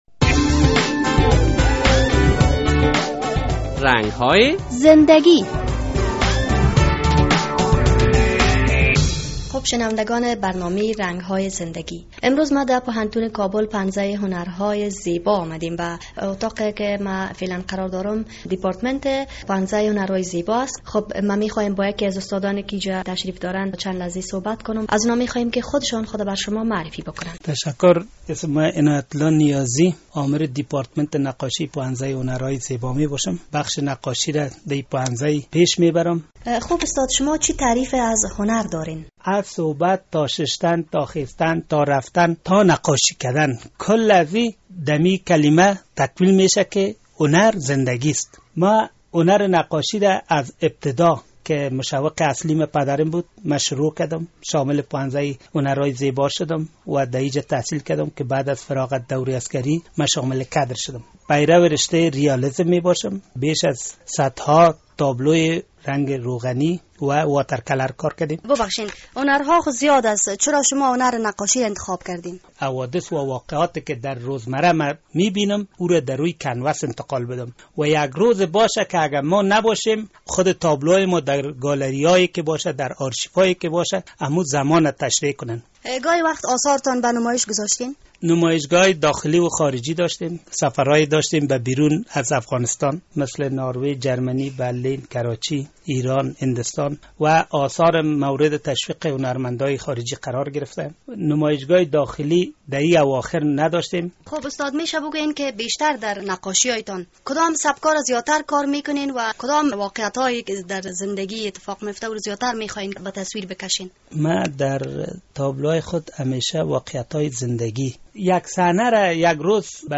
مصاحبهء